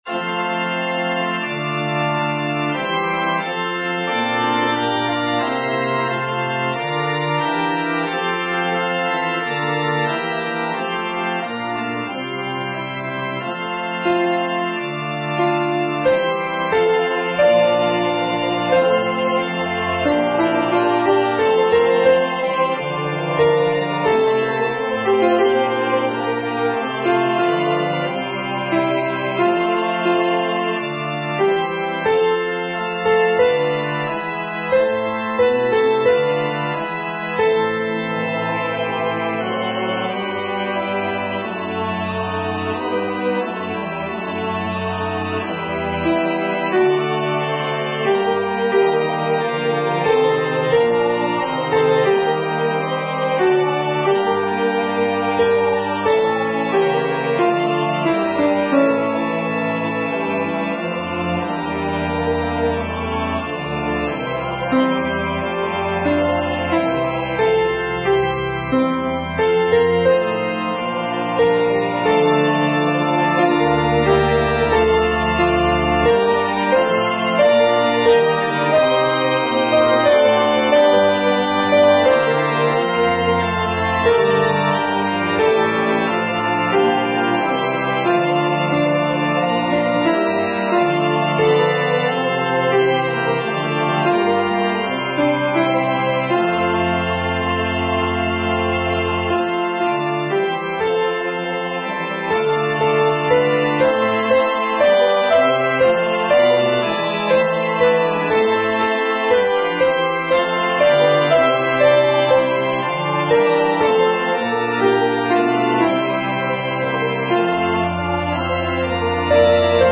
(SA TB Version):
MP3 Practice Files: Soprano/Alto:
Genre: SacredMotet
Instruments: Organ